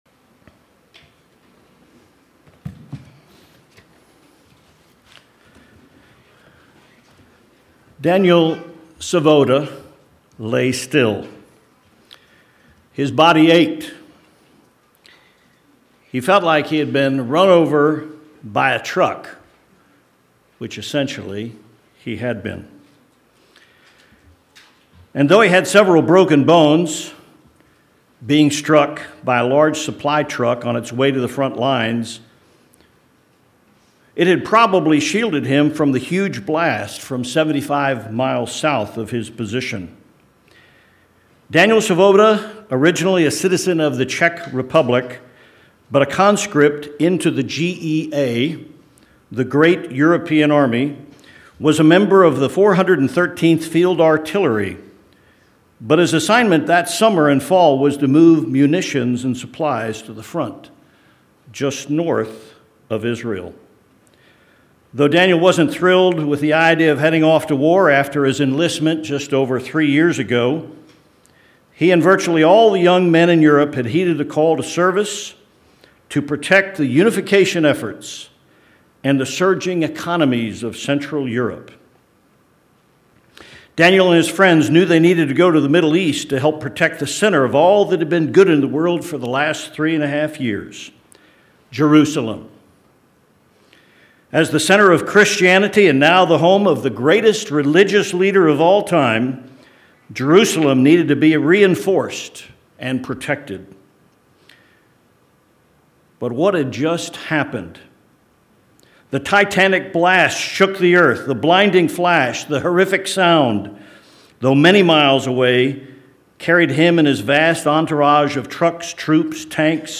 Why are there 10 Days between the Day of Trumpets and the Day of Atonement? This sermon begins with a story about a survivor near Megiddo at the time of Jesus' return and explores the possible reason for the 10 days between these 2 important Holy Days.